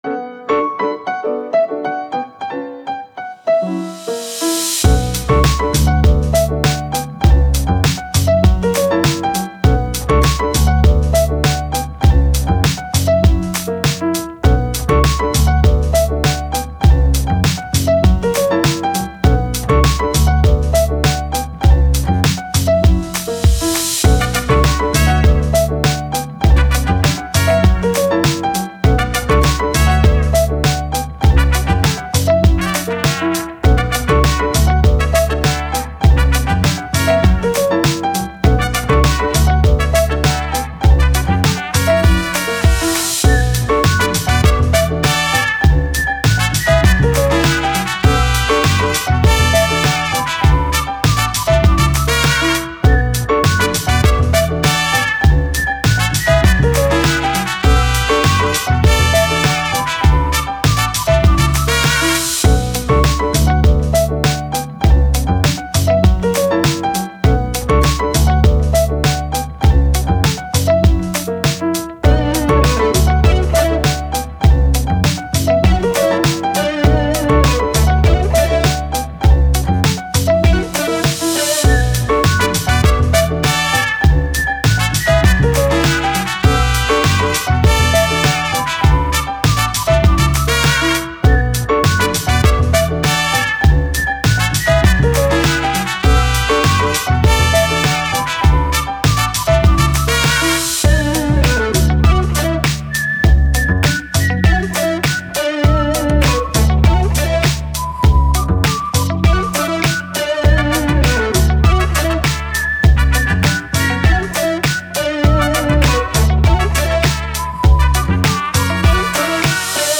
Funky, Hip Hop, Fun, Upbeat